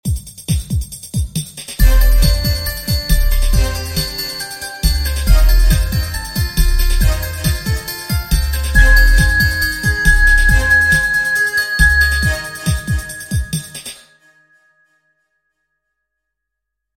Новый трэк, правда слишком мягкий вышел.